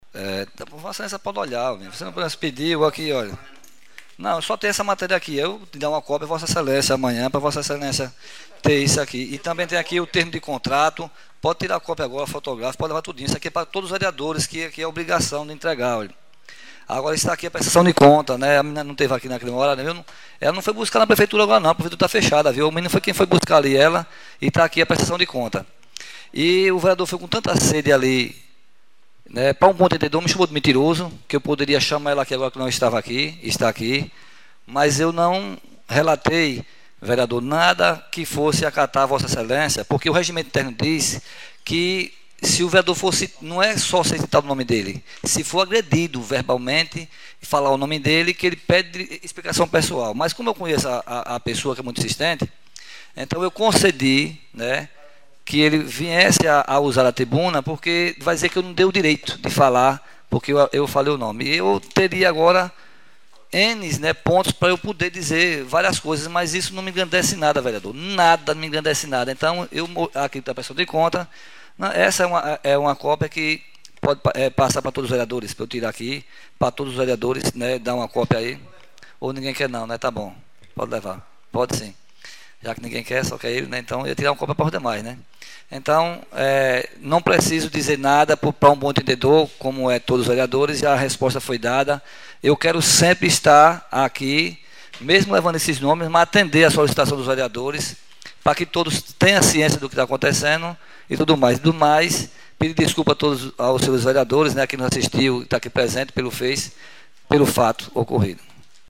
SIMÃO DIAS: AINDA REPERCUTE DEBATE ENTRE PEQUENO SOARES E PRESIDENTE DA CÂMARA; OUÇA
Tags: Câmara , Debate , Jorgeval , Pequeno Soares , Sessão , Vereadores Nenhum comentário Quero comentar!